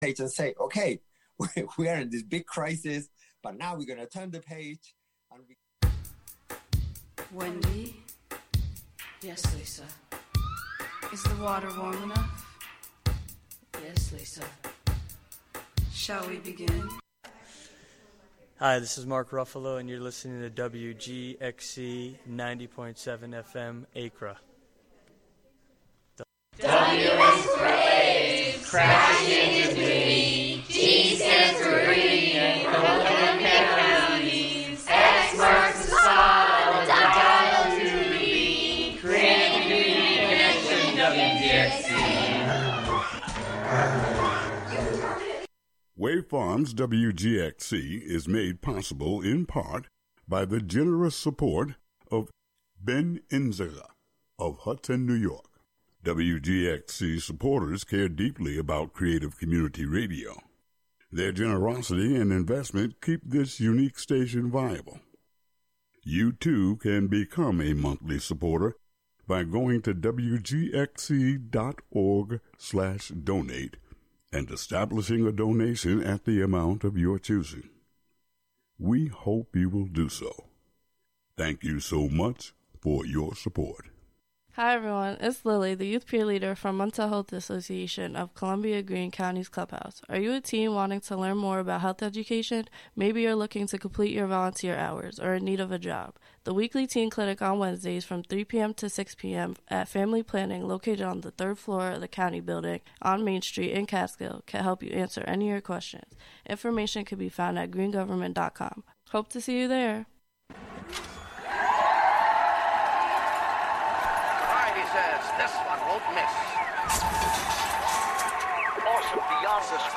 Tune into the “WGXC Afternoon Show” for local news, interviews with community leaders and personalities, reports on cultural issues, a rundown of public meetings and local and regional events, weather updates, and more about and for the community, made by volunteers in the community.